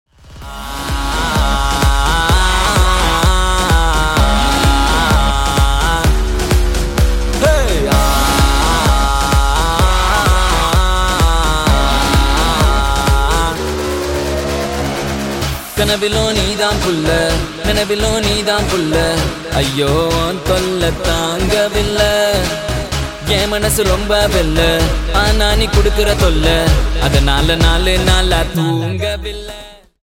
best flute ringtone download | love song ringtone
melody ringtone romantic ringtone